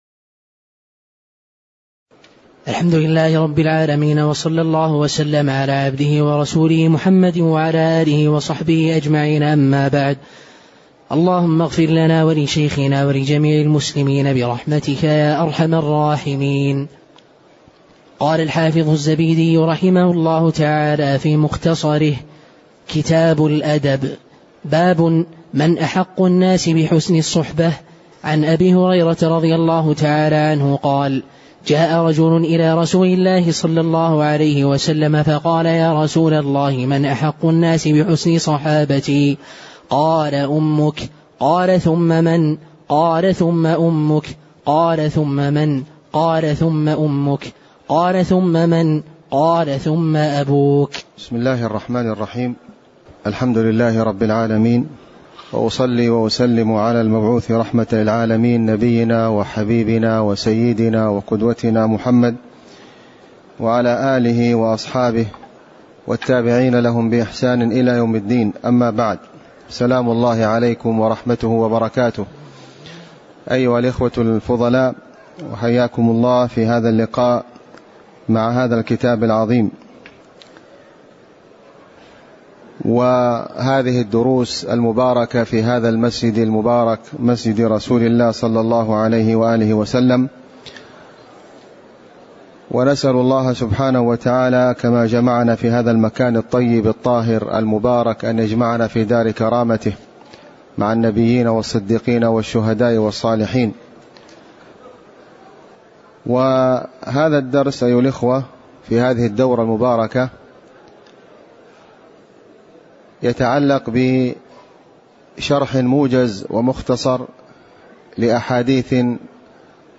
تاريخ النشر ٢٦ ربيع الثاني ١٤٣٩ هـ المكان: المسجد النبوي الشيخ: فضيلة الشيخ د. خالد بن علي الغامدي فضيلة الشيخ د. خالد بن علي الغامدي باب من احق الناس لحسن الصحبه (001)